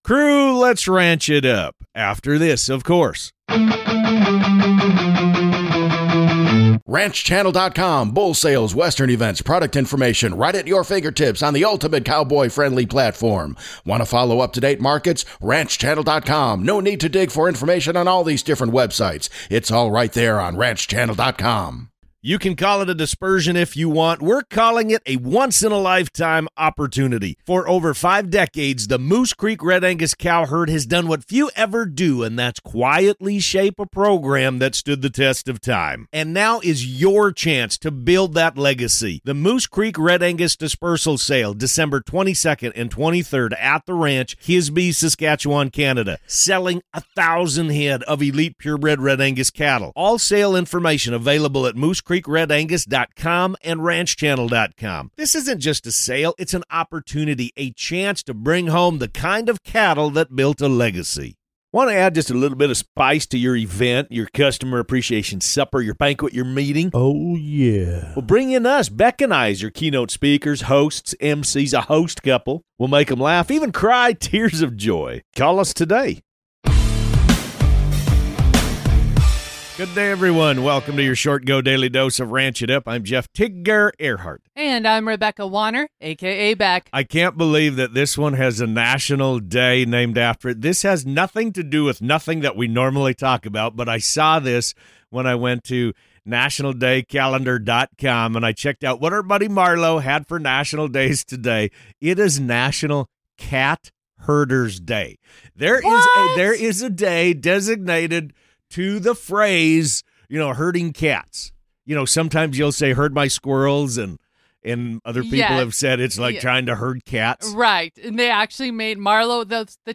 They're serving up the most important ranch-related headlines, from new flavor drops and condiment controversies to the best pairings and fan favorites. Expect insightful (and hilarious) commentary, listener shout-outs, and everything you need to stay in the loop on all things ranch.